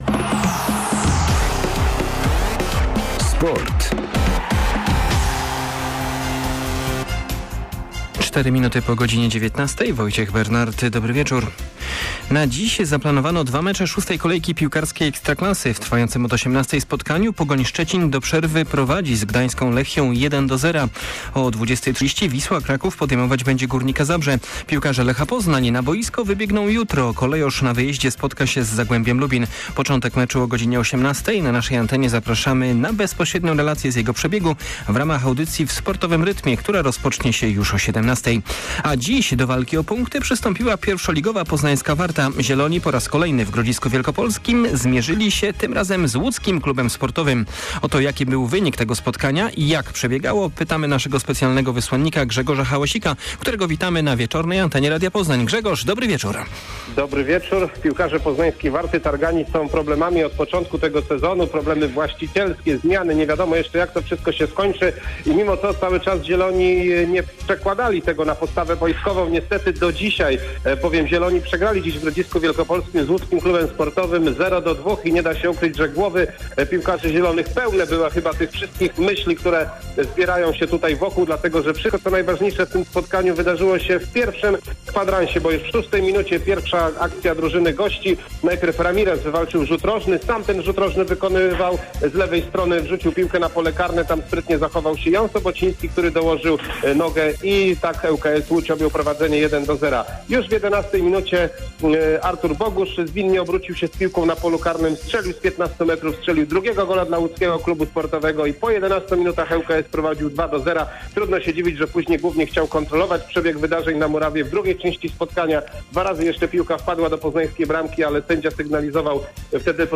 25.08 serwis sportowy godz. 19:05